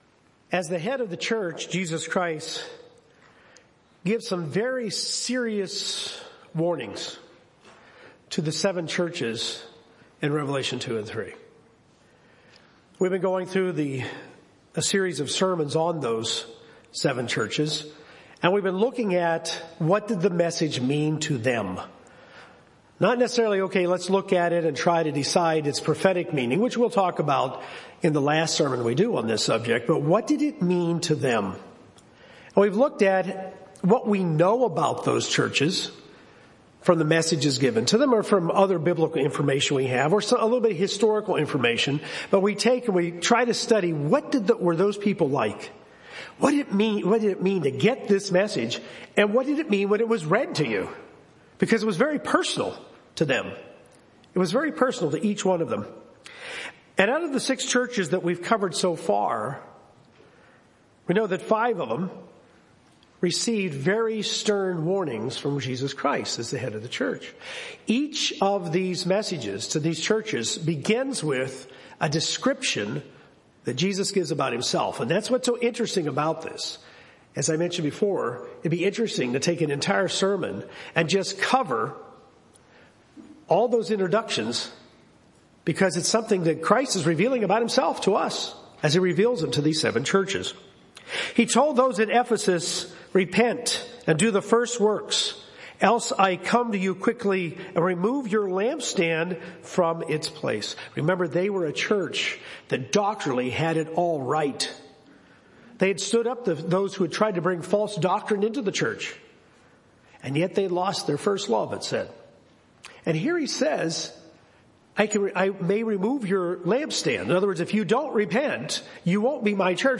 The church in Laodicea is warned using specific descriptions pertaining to the city in which it was located, and Jesus states that He is outside, waiting to be let in. This sermon examines why those warnings are meaningful and how we can avoid becoming like Laodicea.